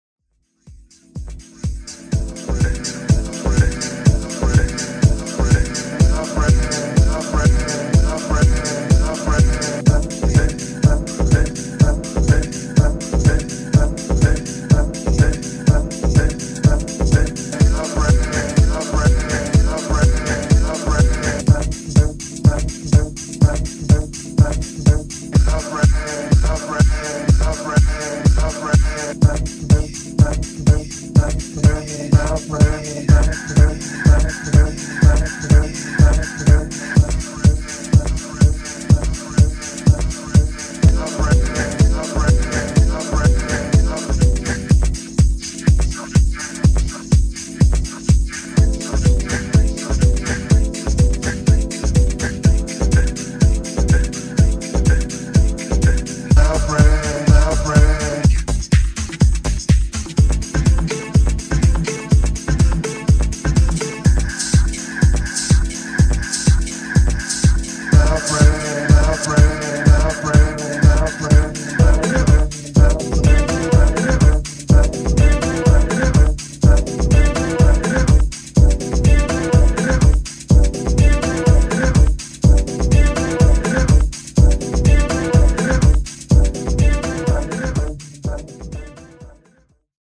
Sexy deep detroit house tracks.
House Detroit